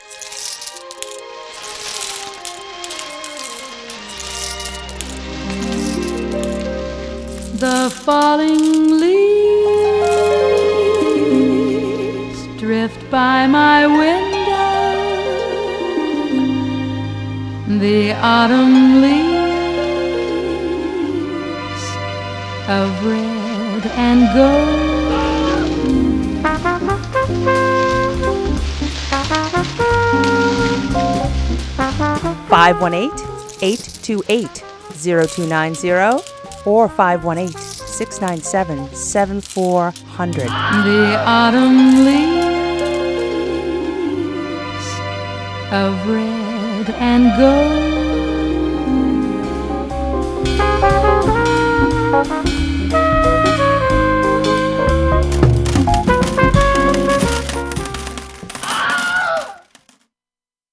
Fall Pledge 2013 Drive Sound Collage (Audio)